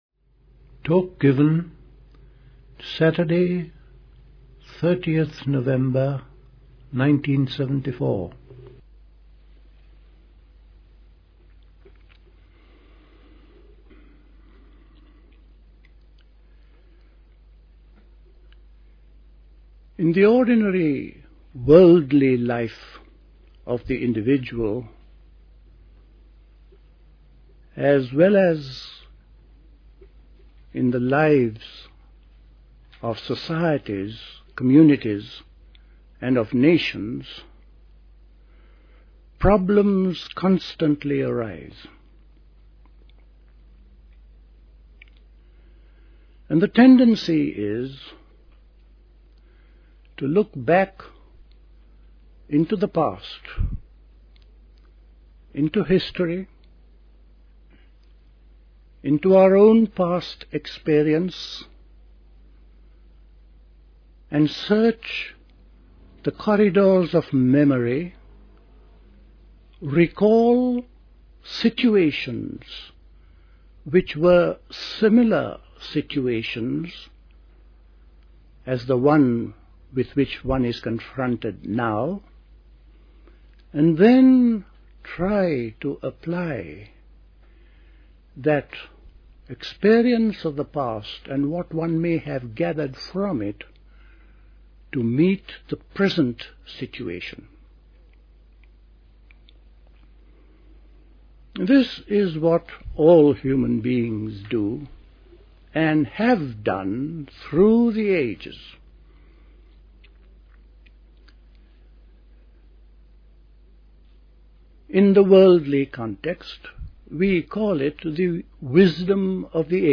A talk
at Dilkusha, Forest Hill, London on 30th November 1974